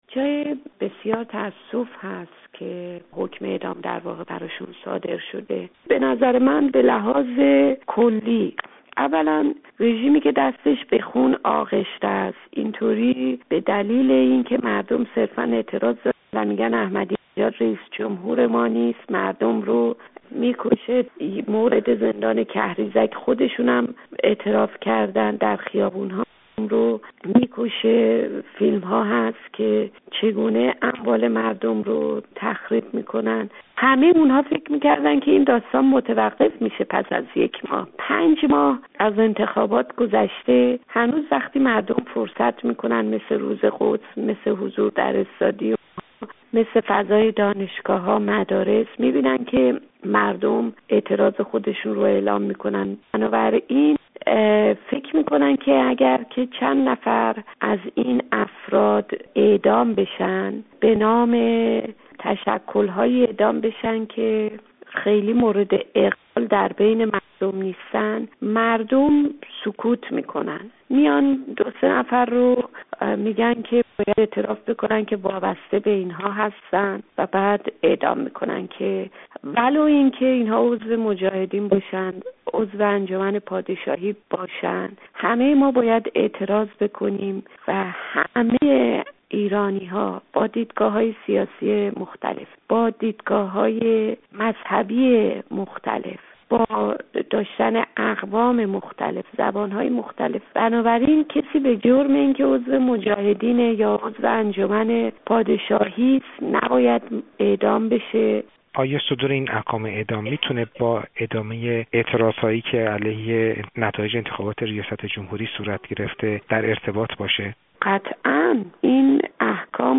گفت‌وگو با فاطمه حقیقت‌جو، نماینده دور ششم مجلس شورای اسلامی